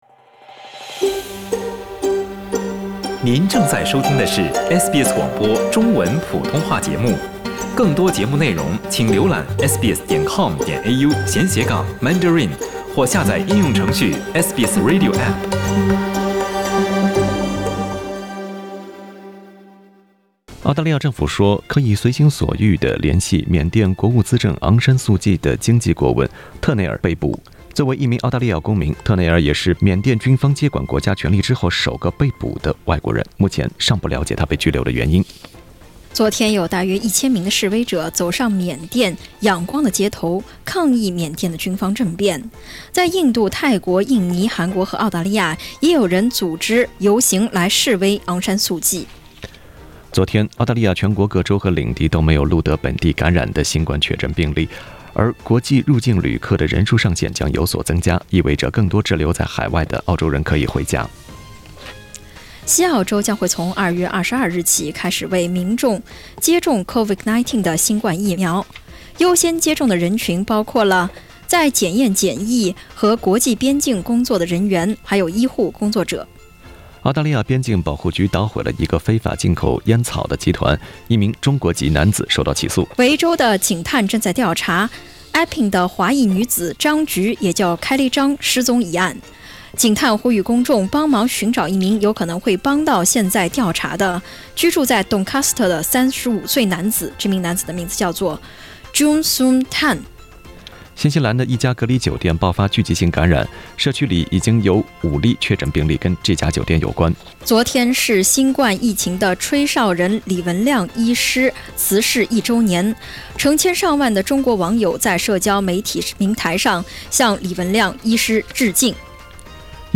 SBS早新聞（2月7日）